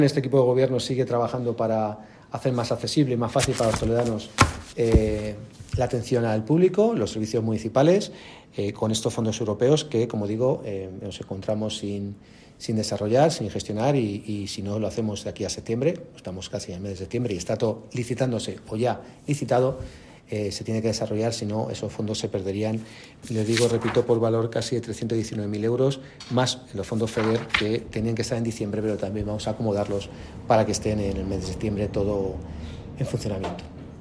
Así lo ha anunciado durante la rueda de prensa celebraba, esta mañana, para valorar los asuntos tratados en la Junta de Gobierno de la Ciudad de Toledo, celebrada el martes, 29 de agosto.
Cortes de voz
corte-de-voz-4-juan-jose-alcalde.mp3